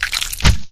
Bubberstation/sound/effects/blob/blobattack.ogg
blobattack.ogg